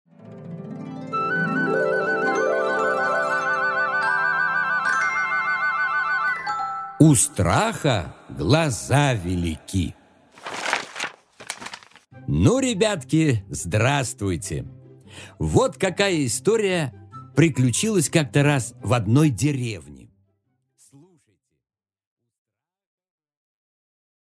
Аудиокнига У страха глаза велики | Библиотека аудиокниг
Прослушать и бесплатно скачать фрагмент аудиокниги